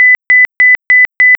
kap140-alert.wav